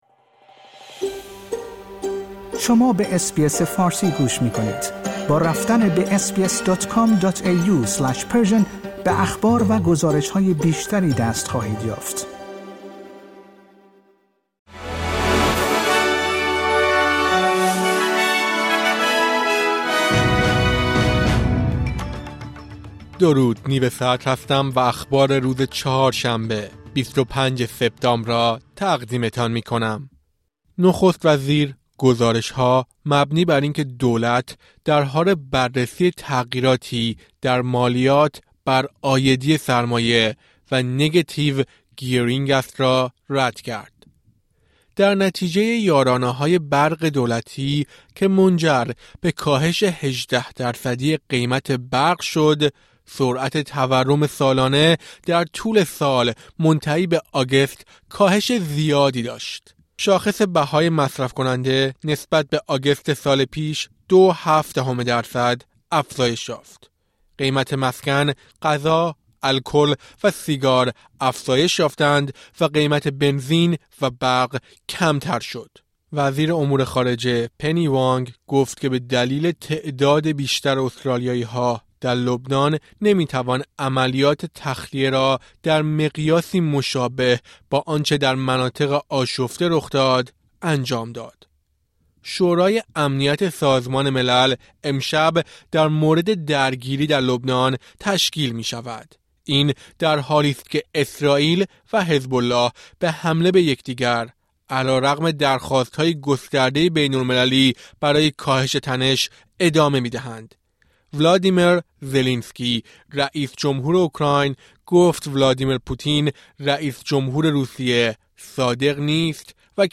در این پادکست خبری مهمترین اخبار استرالیا در روز چهارشنبه ۲۵ سپتامبر ۲۰۲۴ ارائه شده است.